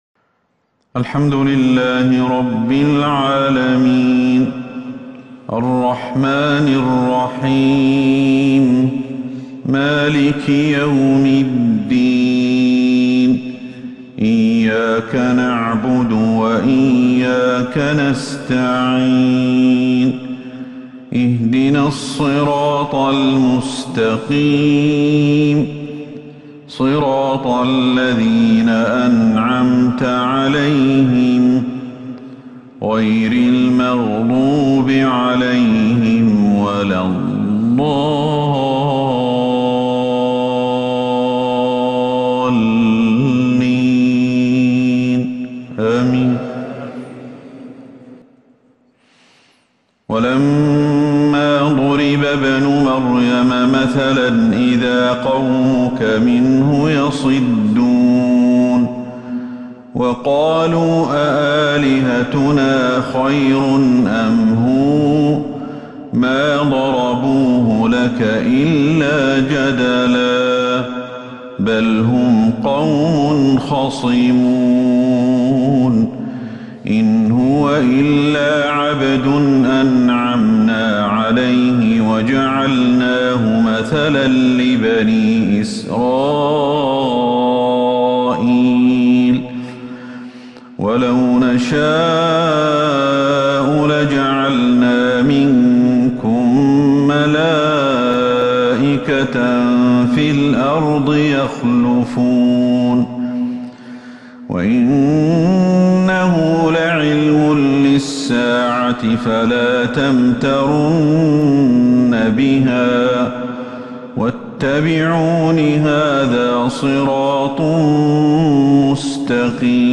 فجر الخميس 19 جمادى الأولى 1443هـ خواتيم سورة {الزخرف} > 1443 هـ > الفروض